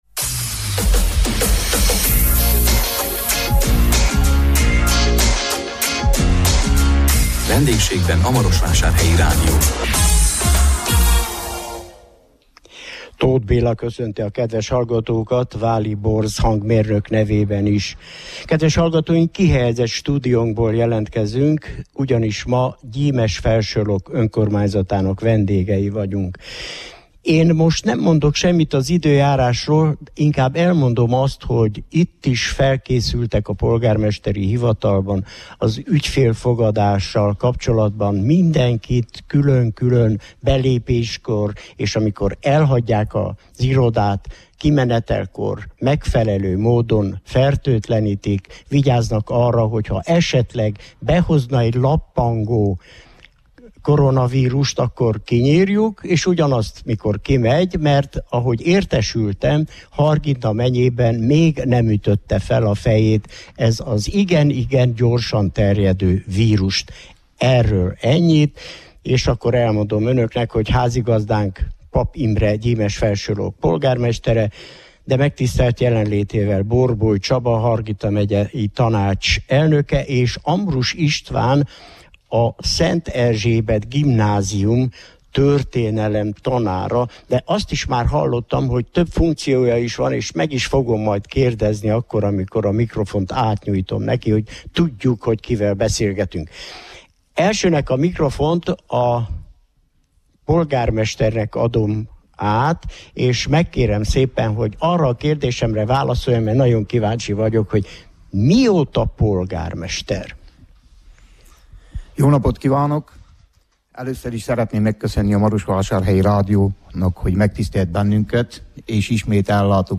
A 2020 március 12-én jelentkező műsorunkban a Hargita megyei Gyimesfelsőlok vendégei voltunk. Meghívottainkkal a beruházásokról és a turizmus nyújtotta lehetőségekről beszélgettünk.